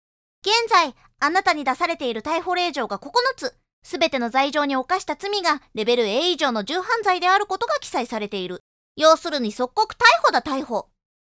サンプルボイス
独特な台詞回しをする謎の少女。